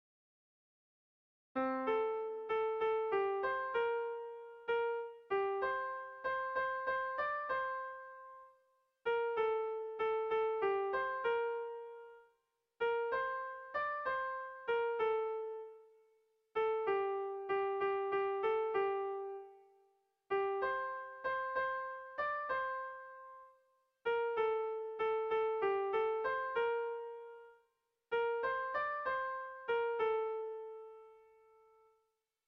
Tragikoa
Zortziko txikia (hg) / Lau puntuko txikia (ip)
A1A2BA2